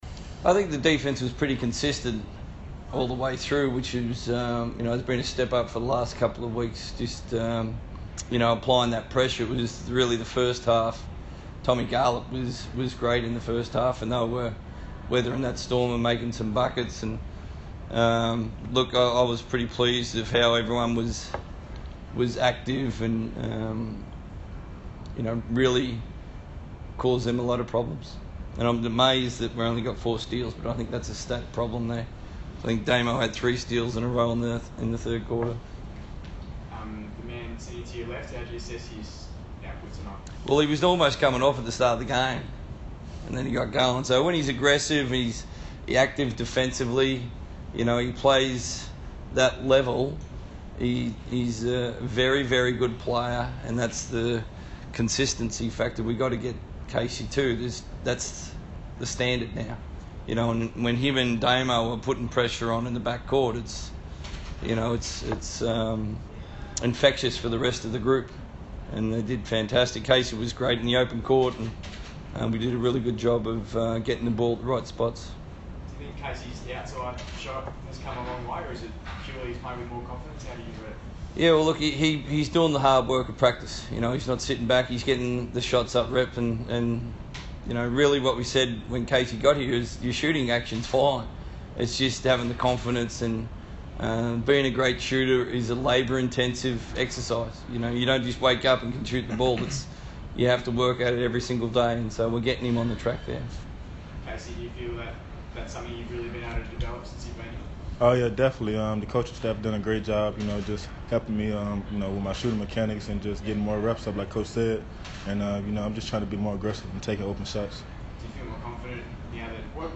speak to the media following the Wildcats win over the Kings.